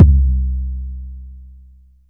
LONG_KIC.WAV